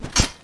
🌲 / foundry13data Data modules soundfxlibrary Combat Single Melee Hit
melee-hit-3.mp3